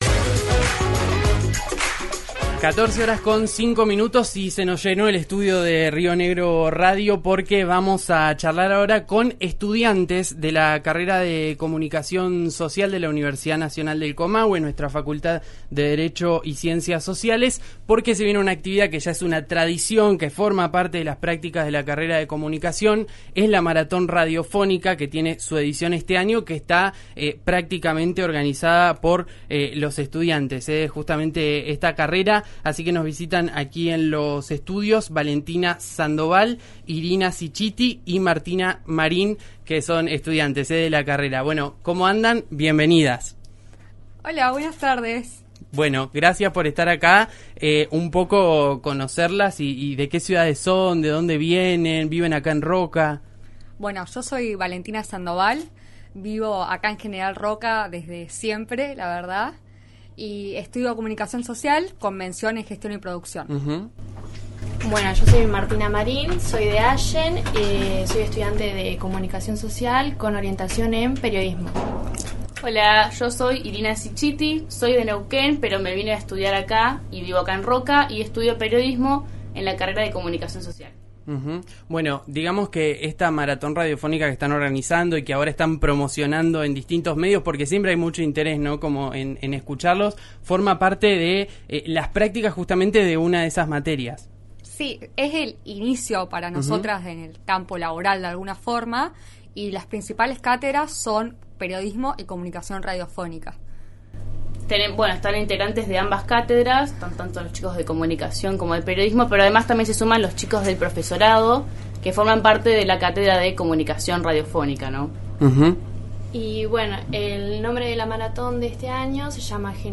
visitaron los estudios de RÍO NEGRO RADIO para contarnos los detalles de esta propuesta.